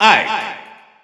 Announcer pronouncing Ike in French.
Ike_French_Announcer_SSBU.wav